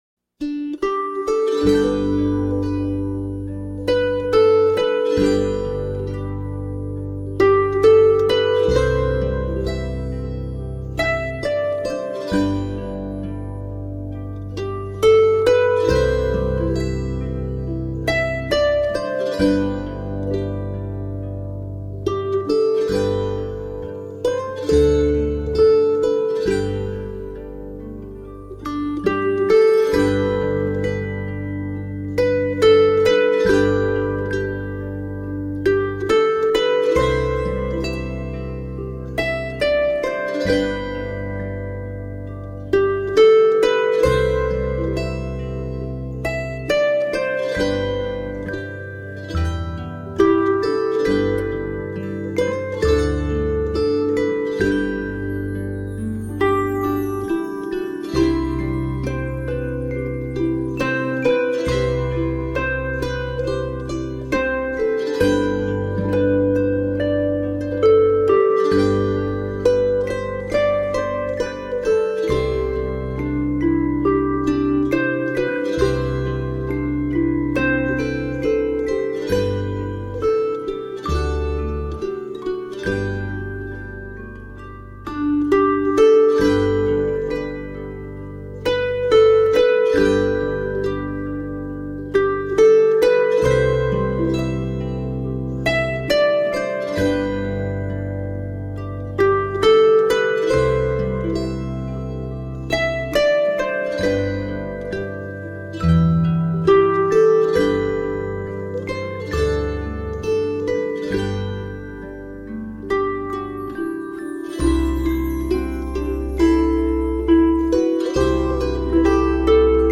Tagged as: World, New Age, Children, Harp